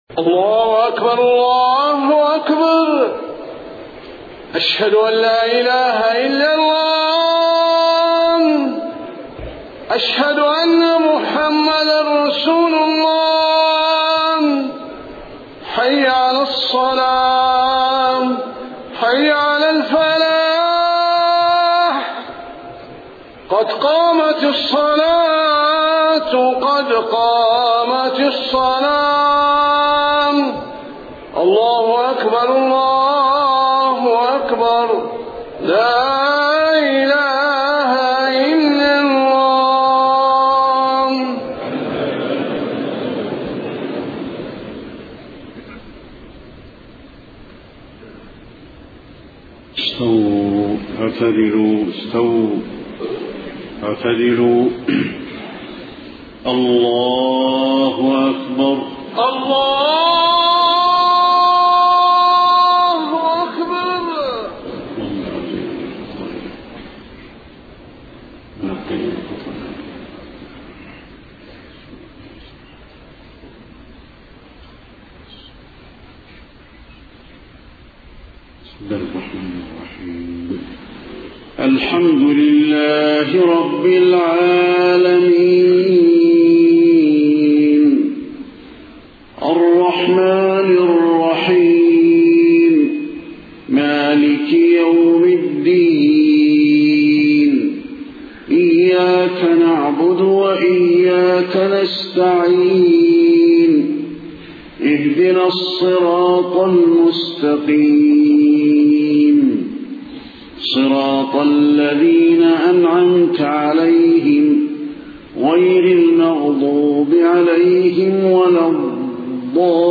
صلاة العشاء 11 ربيع الأول 1431هـ فواتح سورة النجم 1-31 > 1431 🕌 > الفروض - تلاوات الحرمين